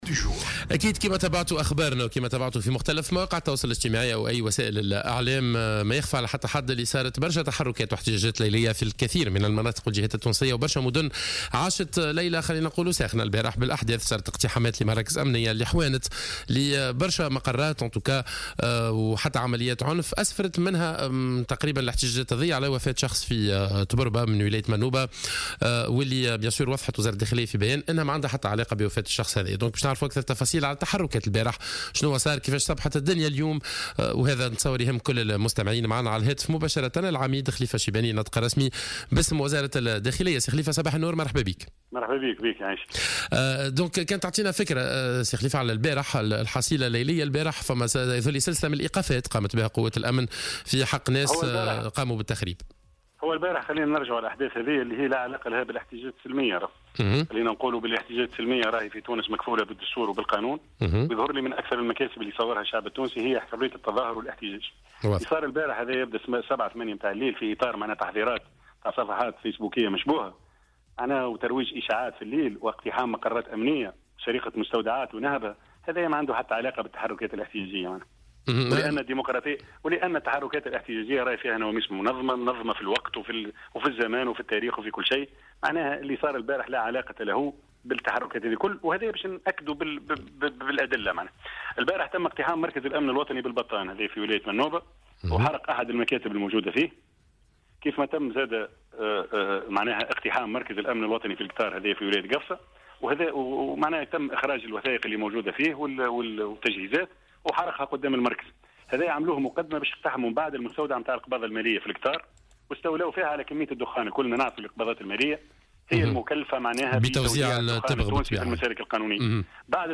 Le porte-parole du ministère de l'Intérieur, le Colonel-major Khalifa Chibani, est intervenu mardi sur les ondes de Jawhara FM, pour faire le point sur la situation sécuritaire du pays, après une nuit marquée par plusieurs manifestations.